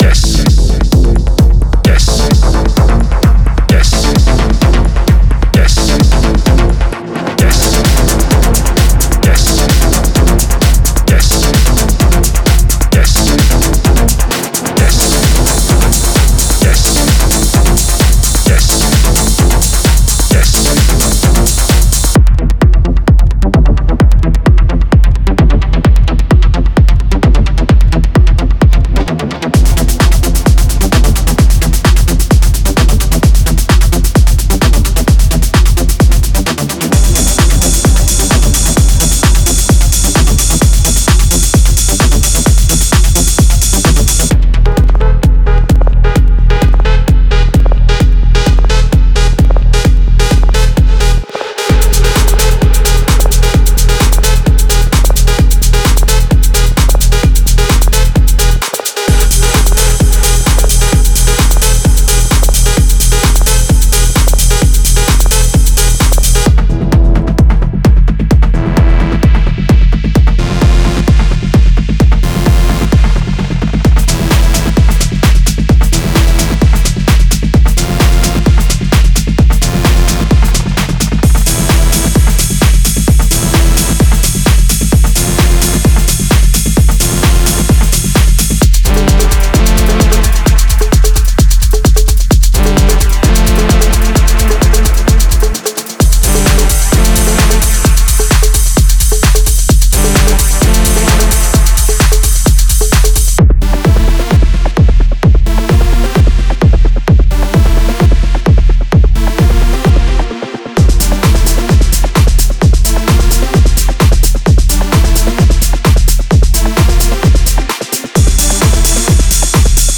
深く共鳴するベースライン、進化するパッド、各音符で物語を紡ぐ表現力豊かなシンセリードを期待できます。
デモサウンドはコチラ↓
Genre:Melodic Techno